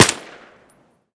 m4a1_sil-1.ogg